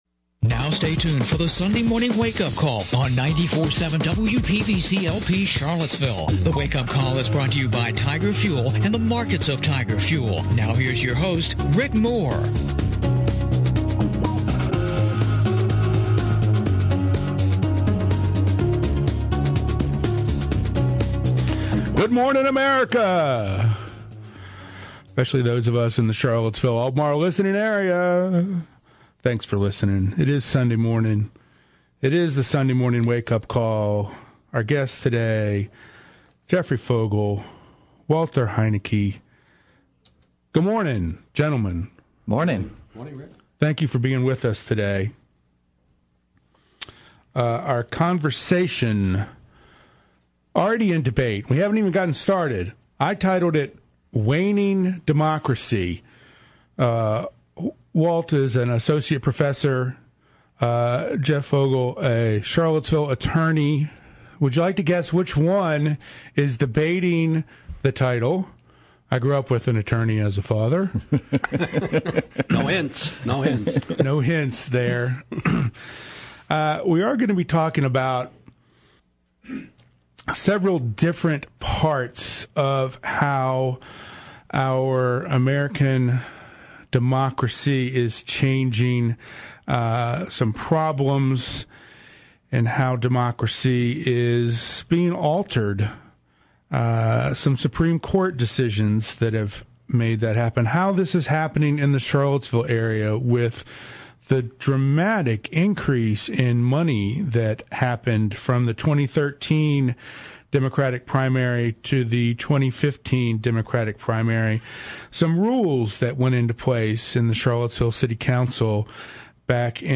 The Sunday Morning Wake-Up Call is heard on WPVC 94.7 Sunday mornings at 11:00 AM.